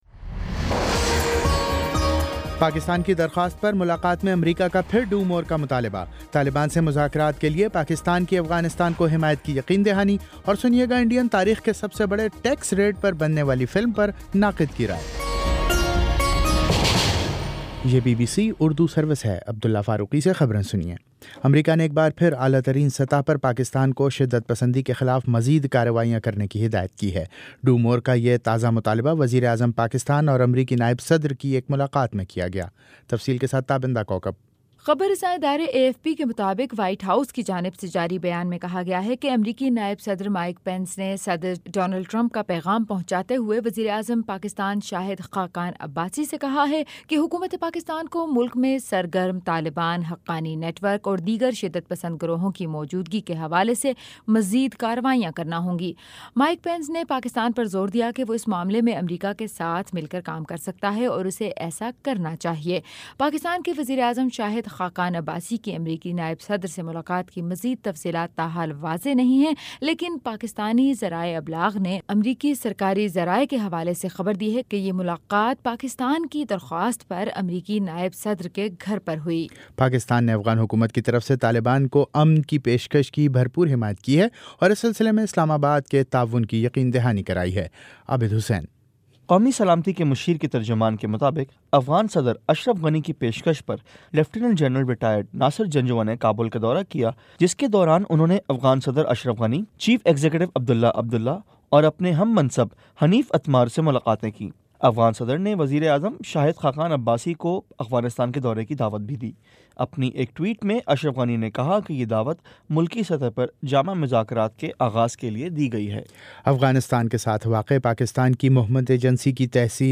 مارچ 18 : شام چھ بجے کا نیوز بُلیٹن
دس منٹ کا نیوز بُلیٹن روزانہ پاکستانی وقت کے مطابق شام 5 بجے، 6 بجے اور پھر 7 بجے۔